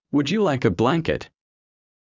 ｳｯ ｼﾞｭｰ ﾗｲｸ ｱ ﾌﾞﾗﾝｹｯﾄ